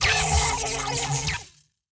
Player Zap Death Sound Effect
Download a high-quality player zap death sound effect.
player-zap-death-1.mp3